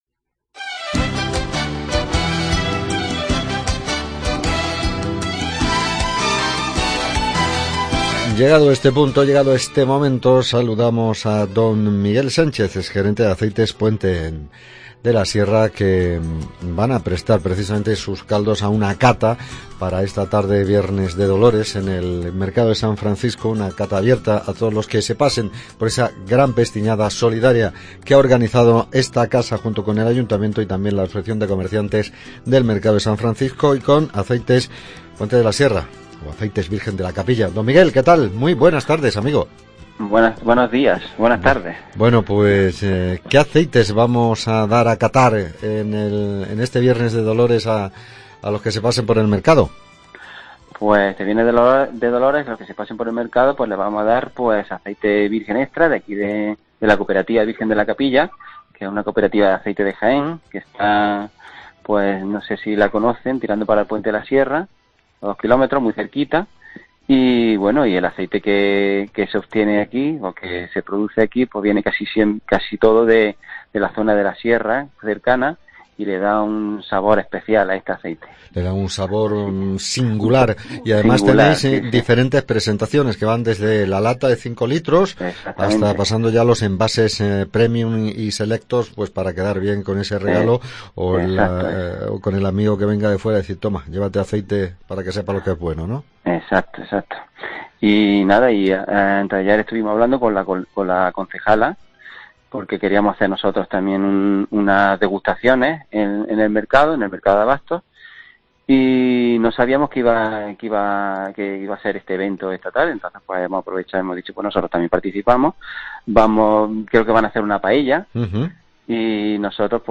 ENTREVISTA ACEITE PUENTE SIERRA